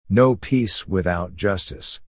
Englisch lernen mit den Wichteln in kurzen, einfachen Lektionen mit Hörbeispielen der Aussprache. - Mini-Lektion 9